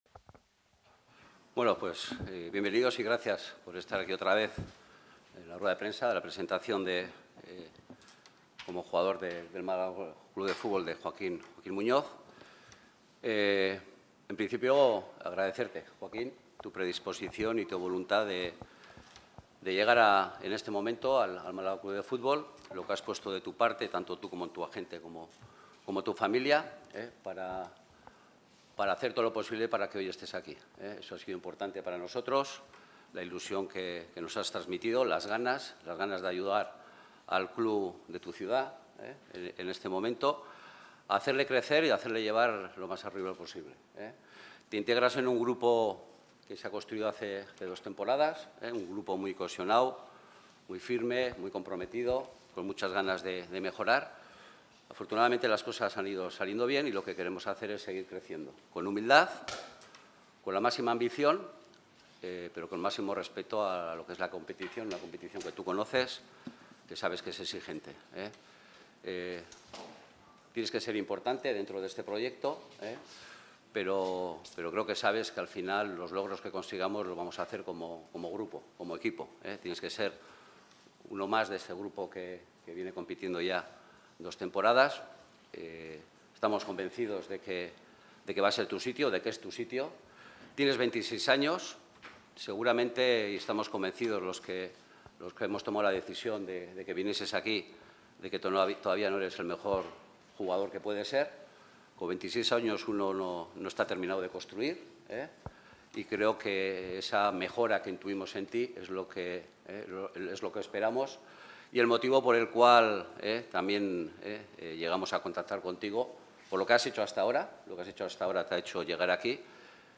Aquí las palabras del extremo en su presentación.